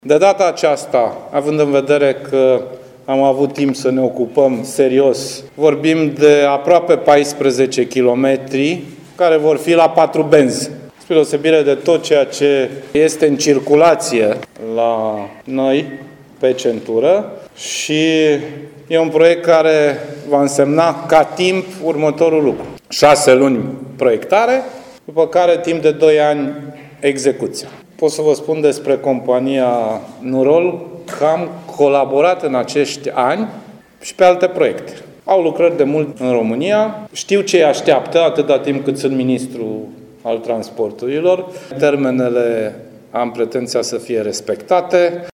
După semnarea documentelor, ministrul Transporturilor, Sorin Grindeanu, a declarat că așteaptă de la firma din Turcia, care a câștigat proiectul în valoare de 1,4 miliarde de lei fără TVA să lucreze bine și să respecte termenele asumate.